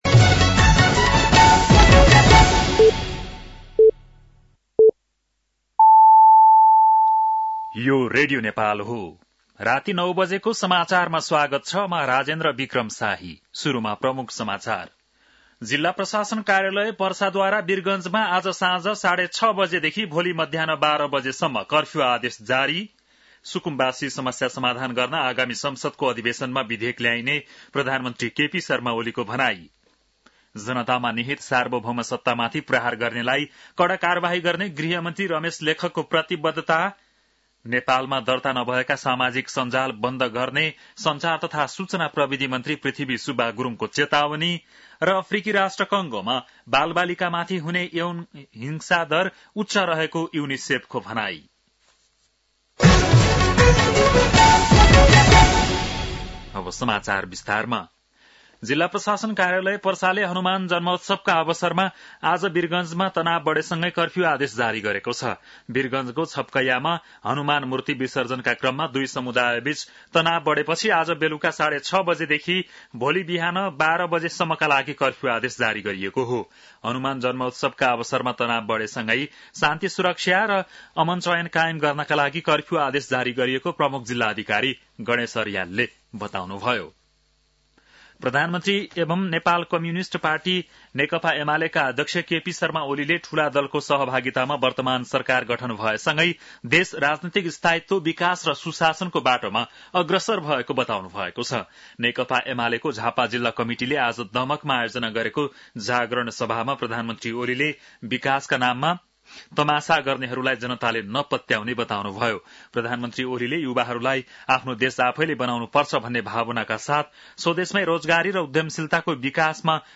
बेलुकी ९ बजेको नेपाली समाचार : ३० चैत , २०८१
9-pm-Nepali-News.mp3